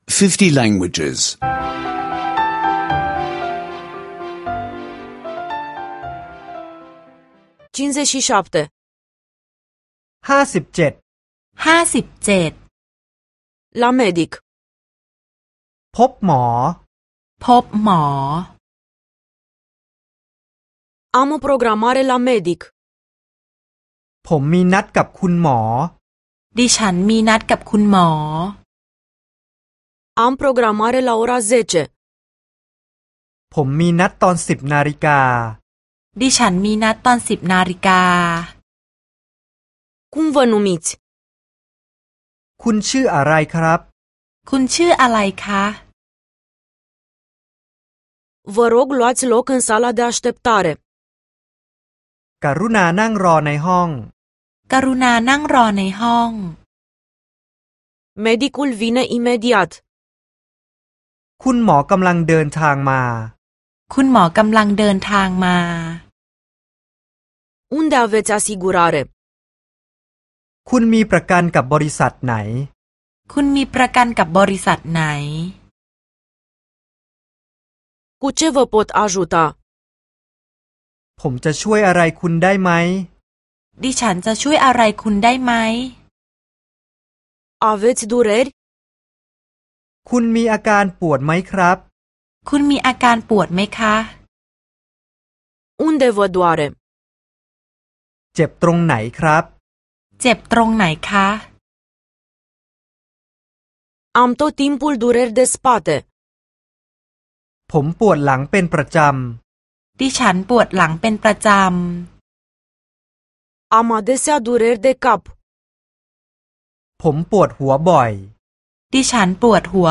Lecții audio de limba thailandeză